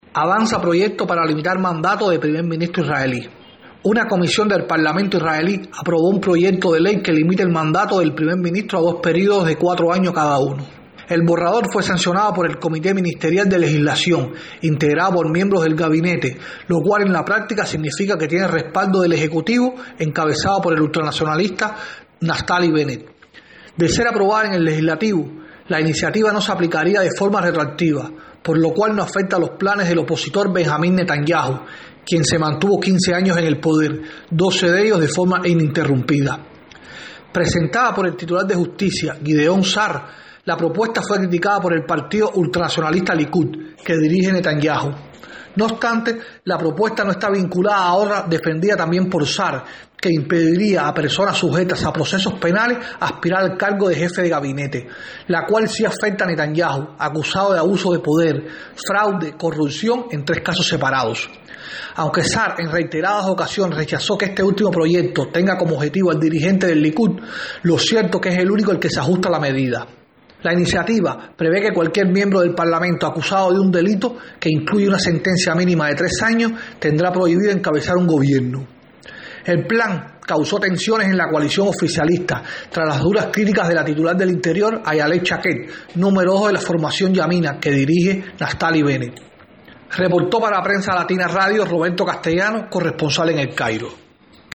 desde El Cairo.